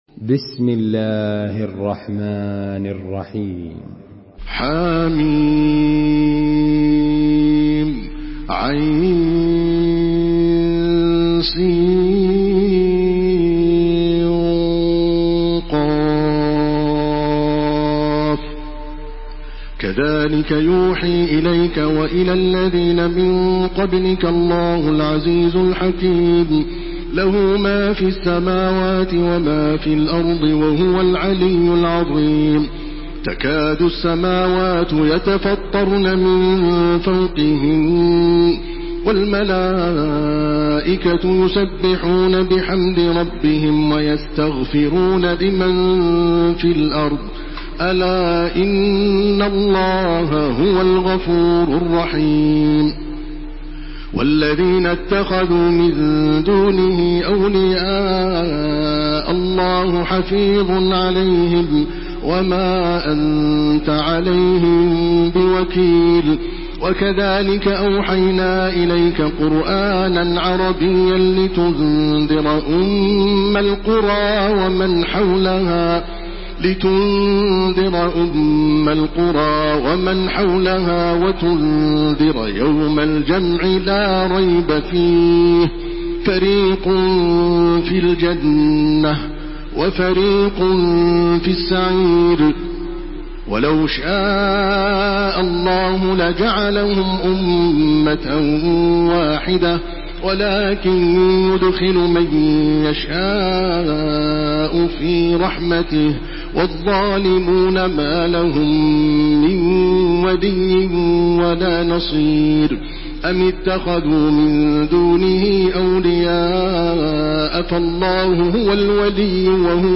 Surah আশ-শূরা MP3 by Makkah Taraweeh 1429 in Hafs An Asim narration.
Murattal Hafs An Asim